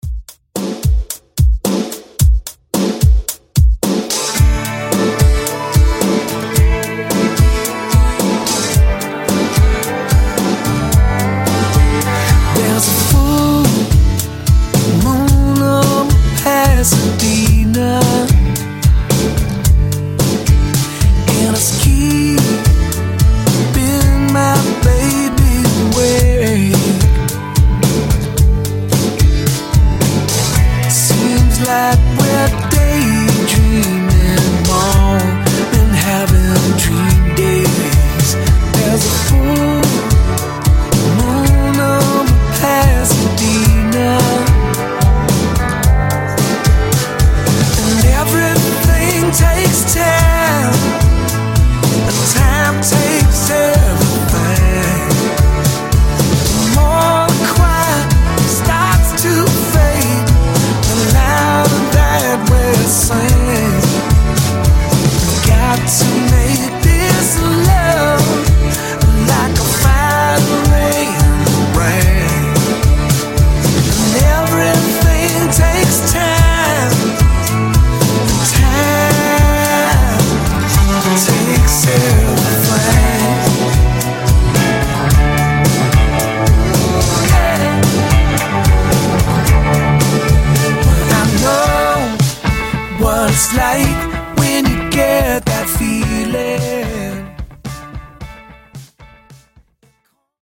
Genres: 80's , RE-DRUM Version: Clean BPM: 108 Time